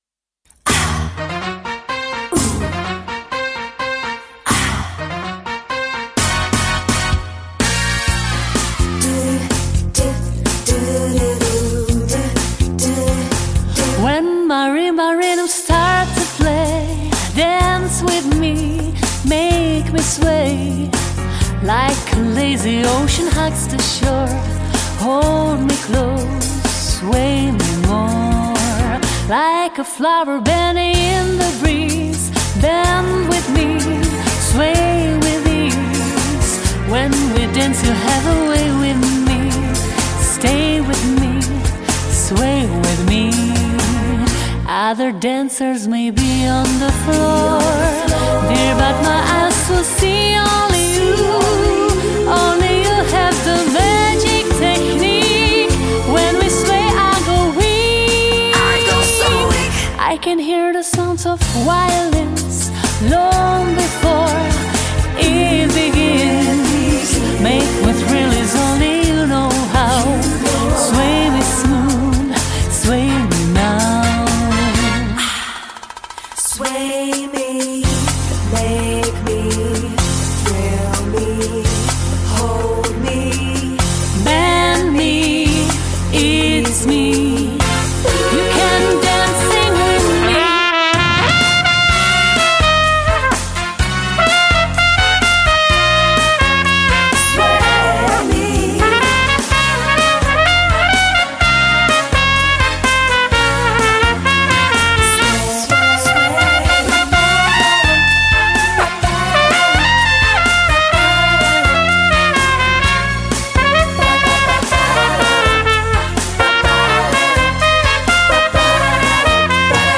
Jazz & Swing